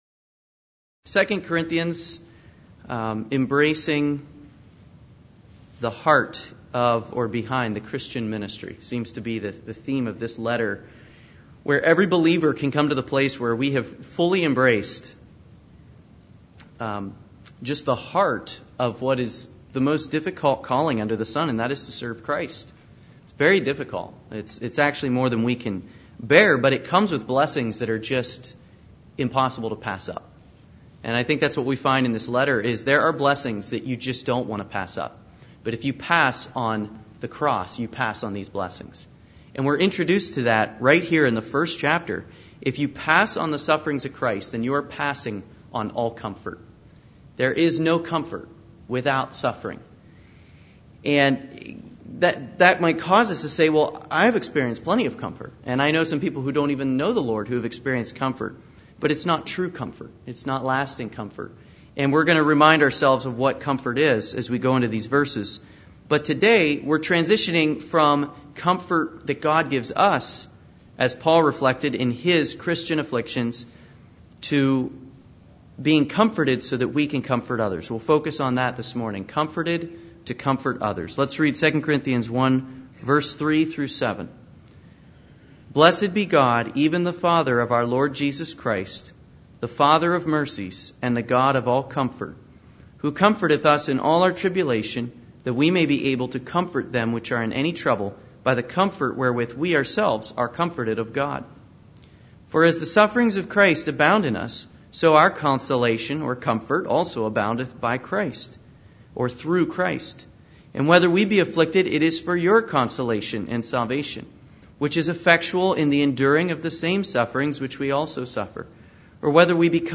Service Type: Special Studies